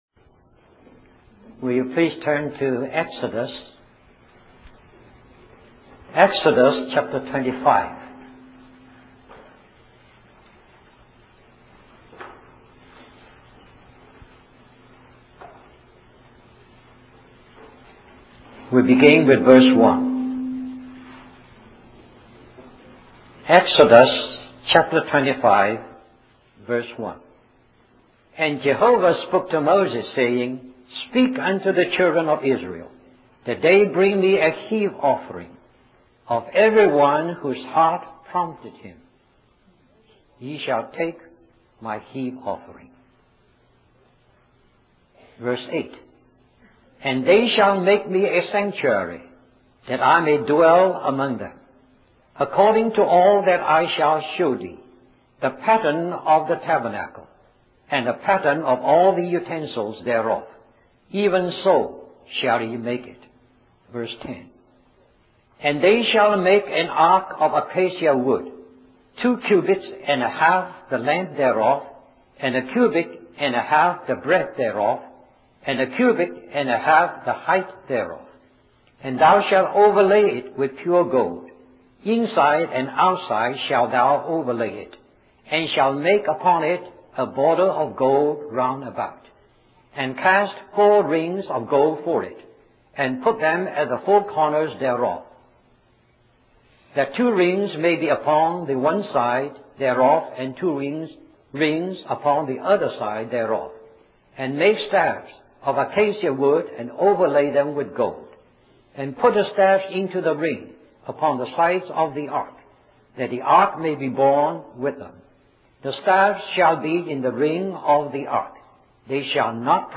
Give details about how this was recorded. Western Christian Conference